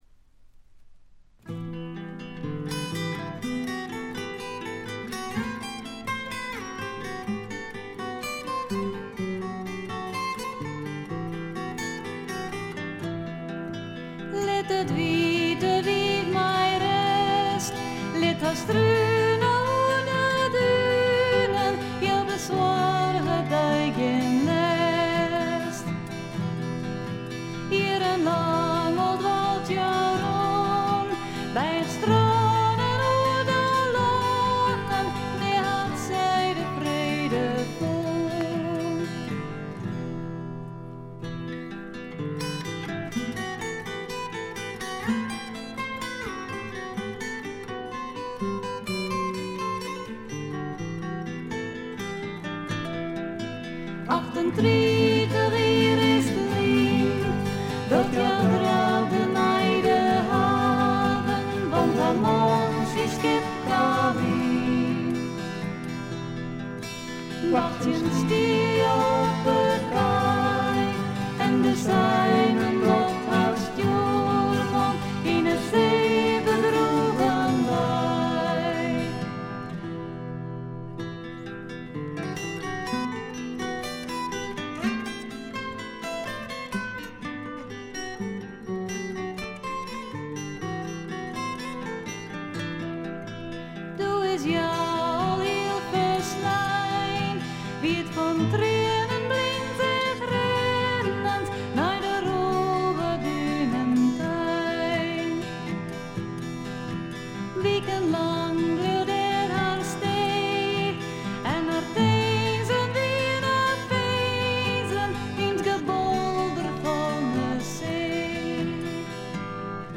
部分試聴ですが、ほとんどノイズ感無し。
アコースティック楽器主体ながら多くの曲でドラムスも入り素晴らしいプログレッシヴ・フォークを展開しています。
試聴曲は現品からの取り込み音源です。